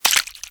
用鱼攻击音效